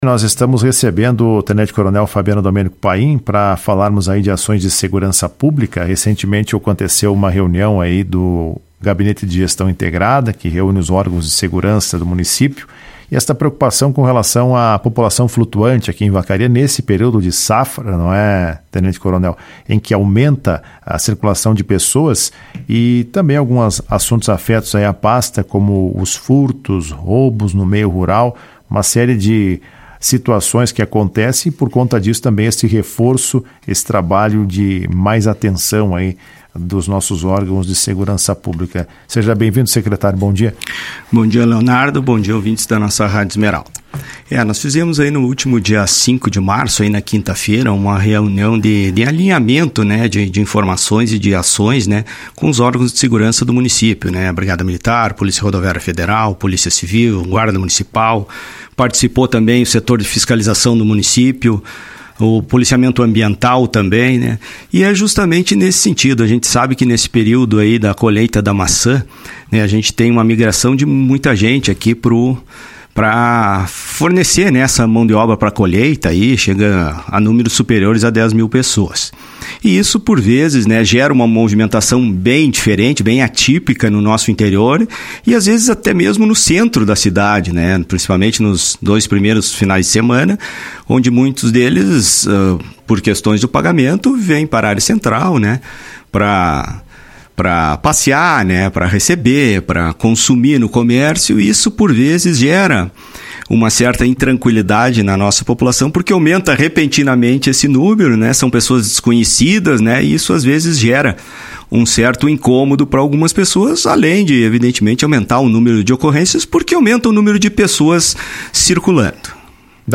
Em entrevista recente à Rádio Esmeralda, o Secretário de Segurança Pública, Fabiano Domênico Paim, destacou que o foco estratégico do GGI está voltado para o combate direto à criminalidade e, especificamente, ao crime de abigeato (furto de animais), que costuma registrar tentativas de alta no interior do município durante a safra.